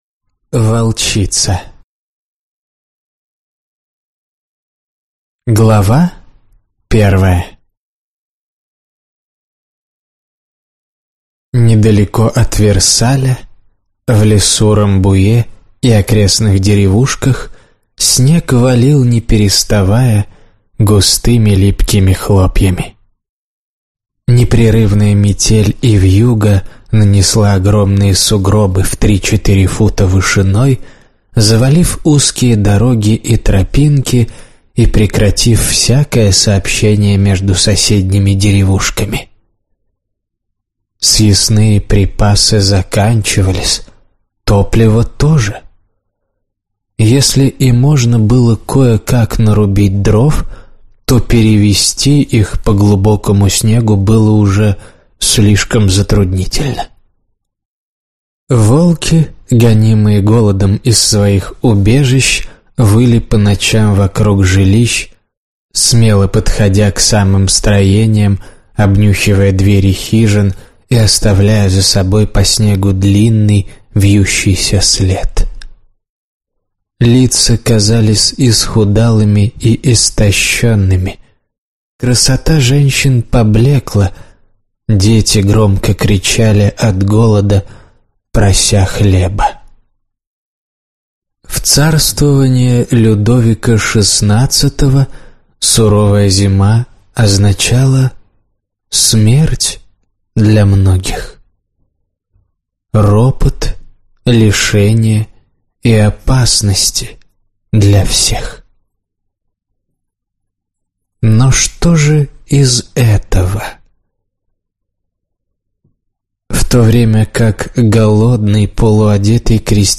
Аудиокнига Волчица | Библиотека аудиокниг